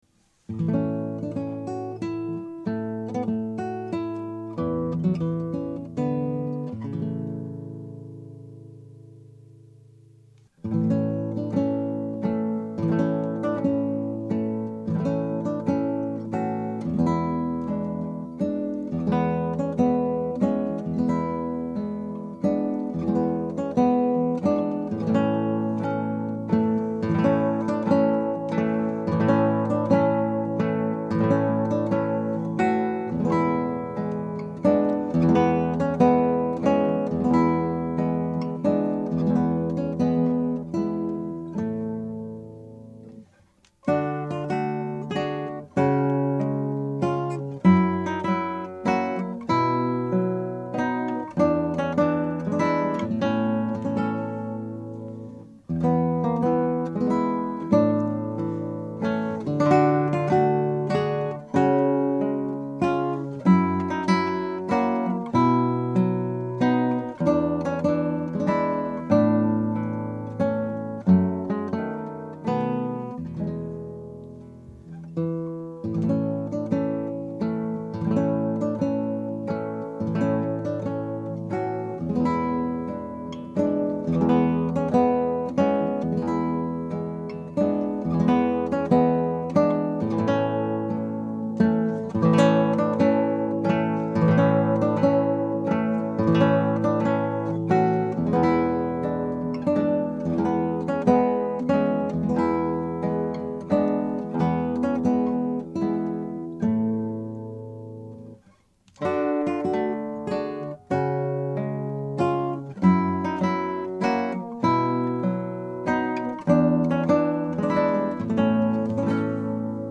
Scraps from the Operas arranged for Two Guitars
Scrap: Andante.
At 2:21 there is a variation on the main theme that is not heard in the opera.